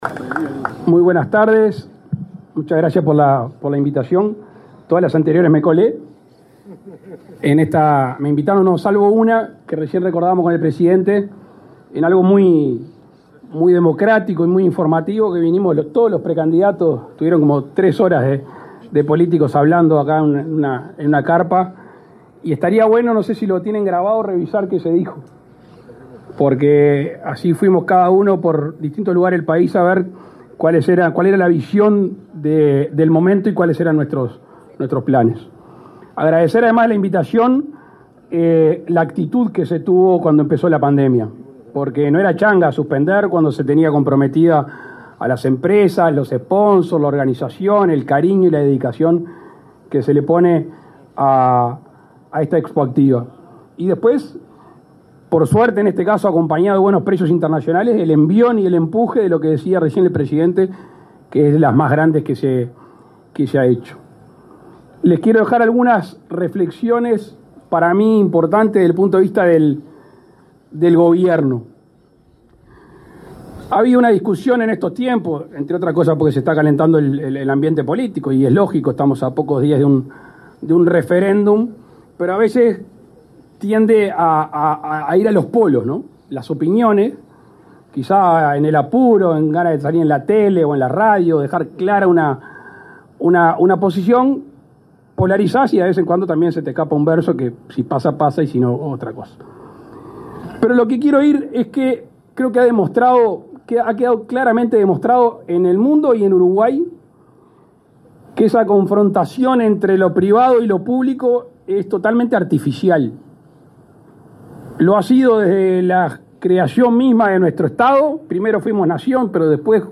Declaraciones a la prensa del presidente de la República, Luis Lacalle Pou
Declaraciones a la prensa del presidente de la República, Luis Lacalle Pou 15/03/2022 Compartir Facebook X Copiar enlace WhatsApp LinkedIn Tras participar en la inauguración de la Expo Activa 2022, en Soriano, este 15 de marzo, el presidente Luis Lacalle Pou efectuó declaraciones a la prensa.